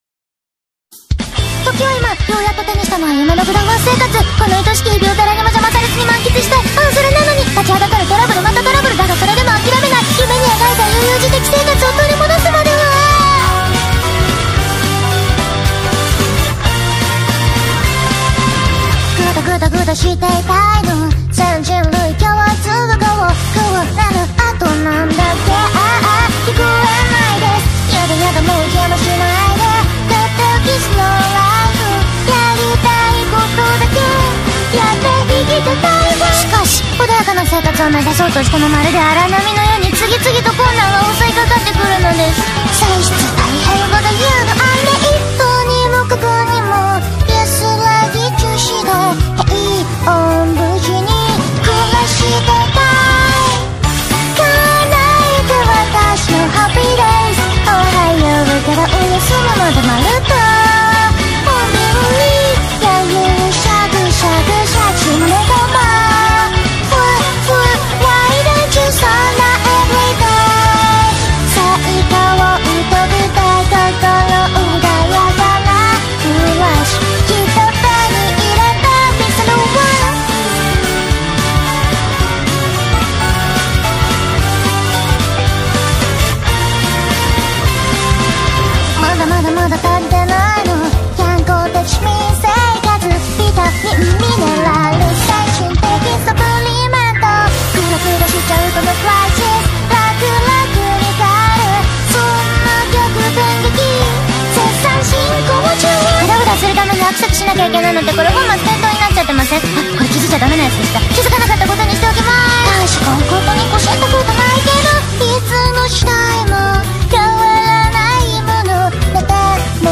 Opening Theme